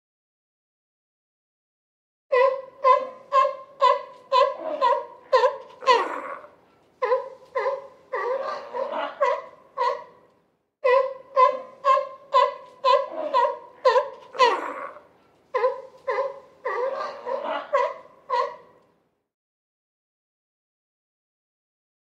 На этой странице собраны разнообразные звуки морских львов – от их громкого рычания до игривого плеска в воде.
Голос морского льва